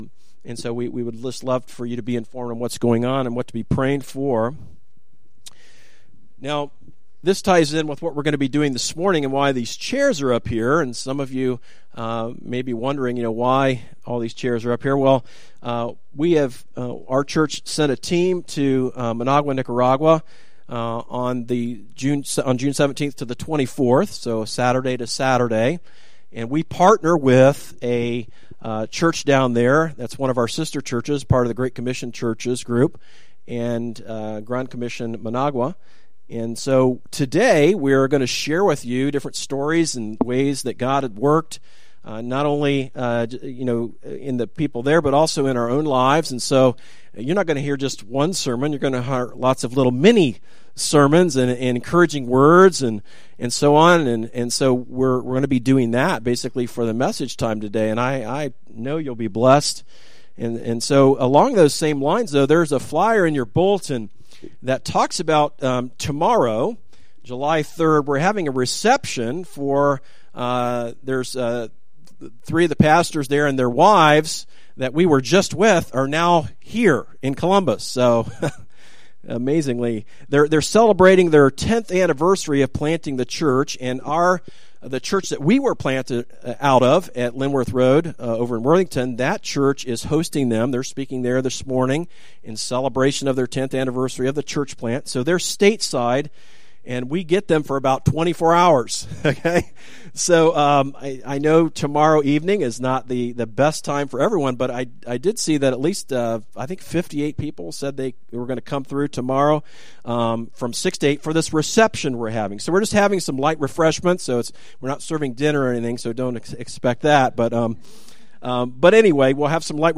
Sermons that are not part of a series